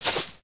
sounds / material / human / step / grass03.ogg
grass03.ogg